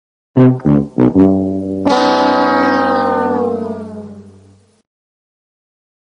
Losing Horn
Category: Sports   Right: Personal